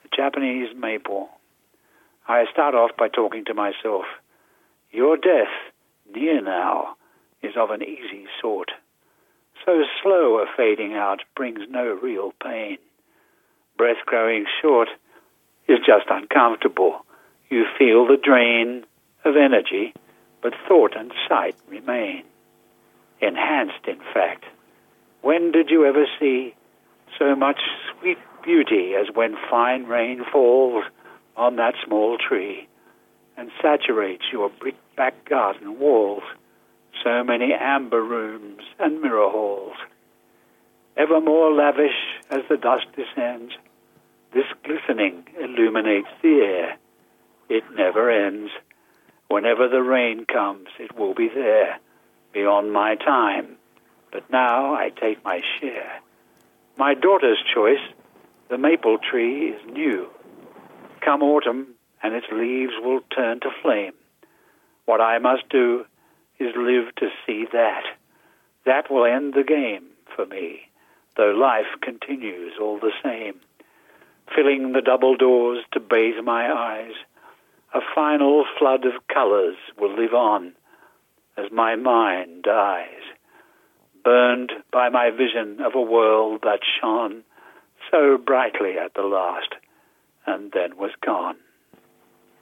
Clive James Reads "The Japanese Maple"